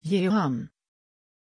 Prononciation de Jehan
pronunciation-jehan-sv.mp3